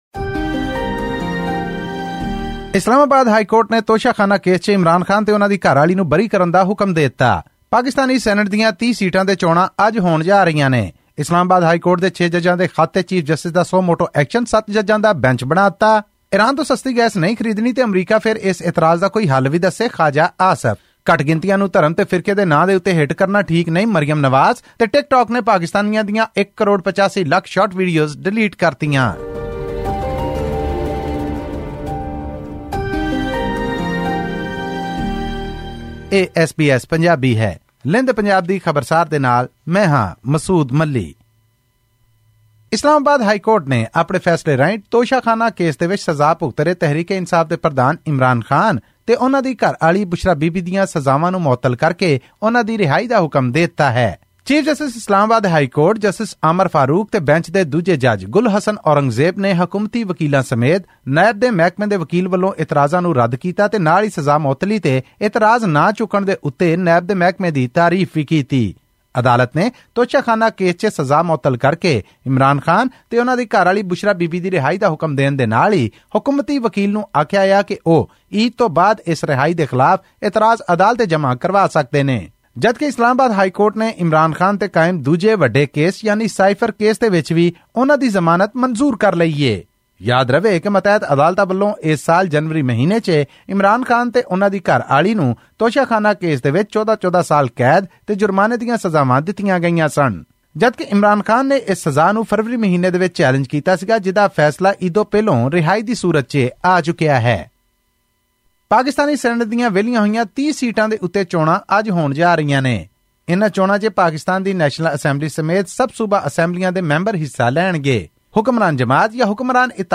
ਹੋਰ ਵੇਰਵੇ ਲਈ ਸੁਣੋ ਇਹ ਆਡੀਓ ਰਿਪੋਰਟ.....